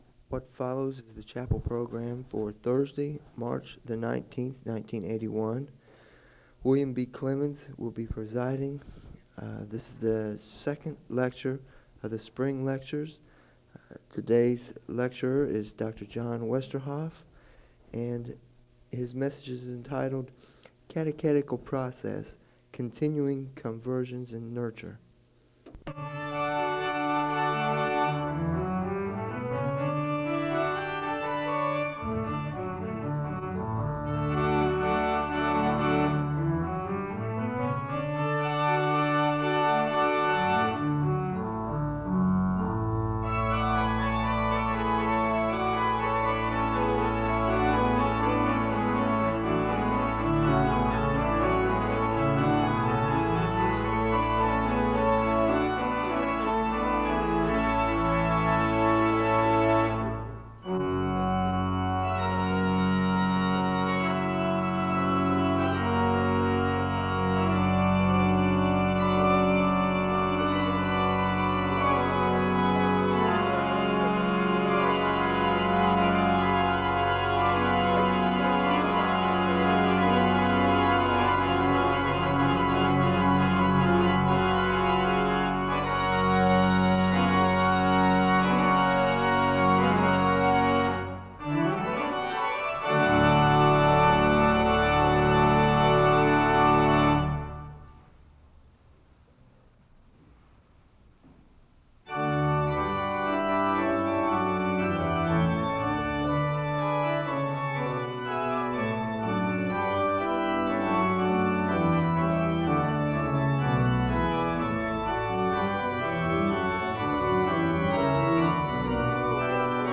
The service begins with organ music (0:00:00-0:06:10). The speaker reads from the Scriptures...